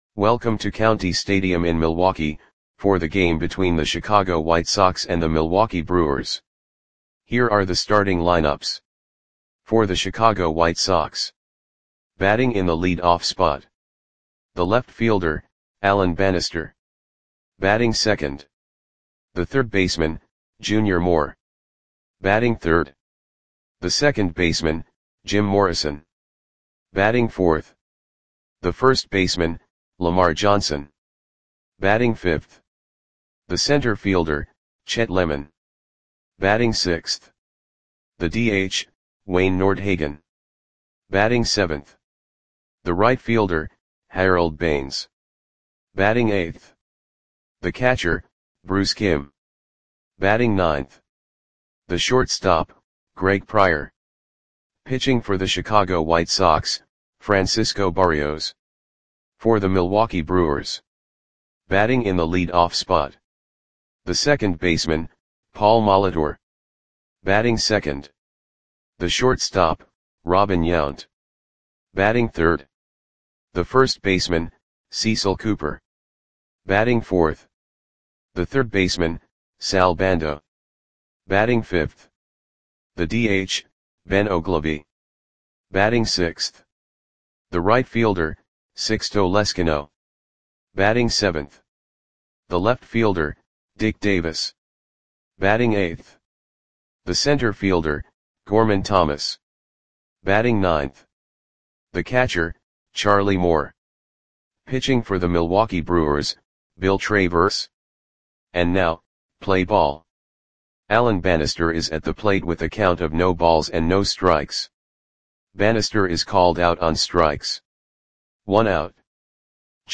Audio Play-by-Play for Milwaukee Brewers on May 13, 1980
Click the button below to listen to the audio play-by-play.